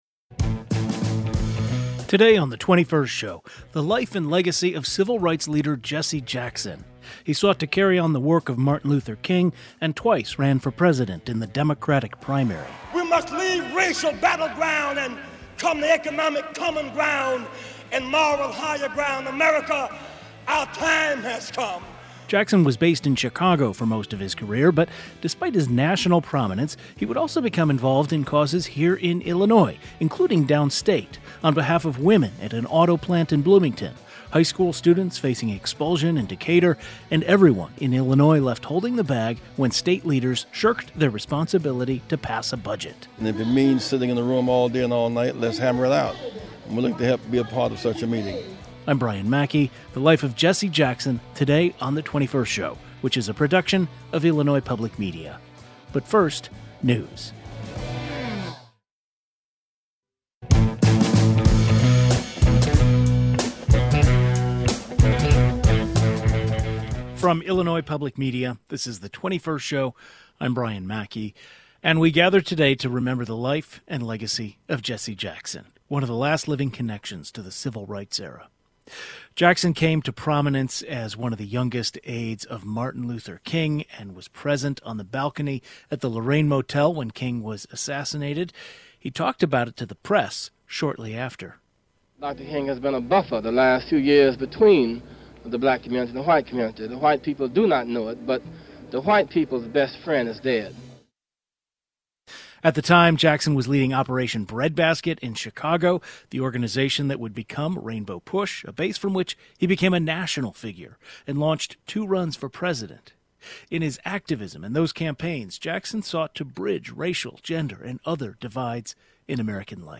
A panel of Illinois-based Black leaders discuss their personal connections with Jackson and his role in the Civil Rights movement and in politics.